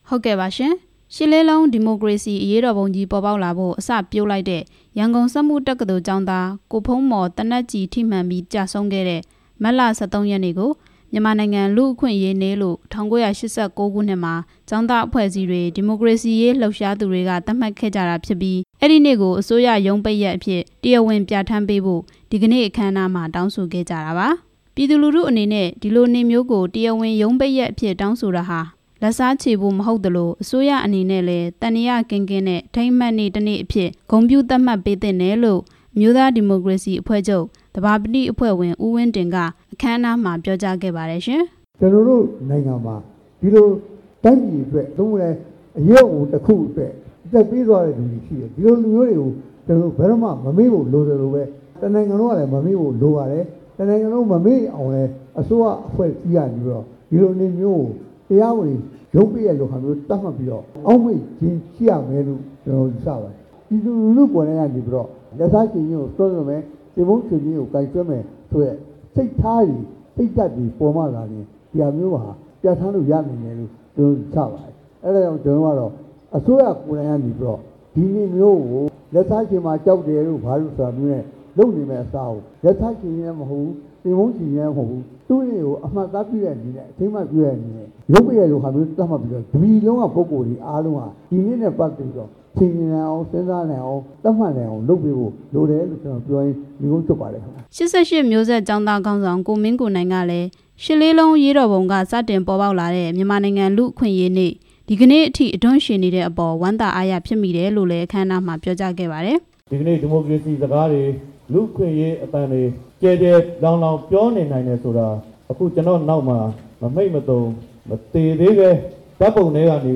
ကျောင်းသားနဲ့ နိုင်ငံရေးပါတီ ခေါင်းဆောင်တွေ ပြောကြားချက်